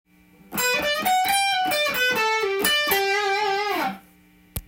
④のフレーズは、最後の音程が③と同じ音をたどりますが
使用しているスケールがGメジャースケールまたはEマイナースケールを
使っているので滑らかでポップスな感じがします。